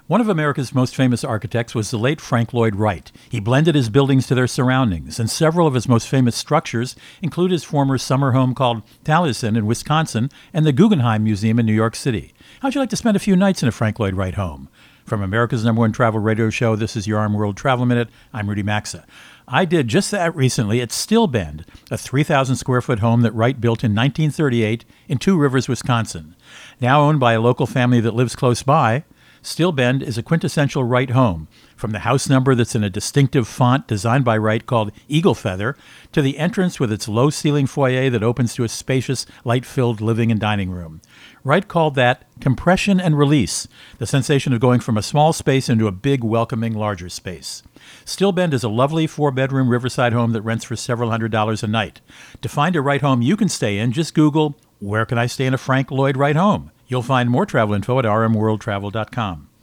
Co-Host Rudy Maxa | Frank Lloyd Wright’s Home is Awaiting…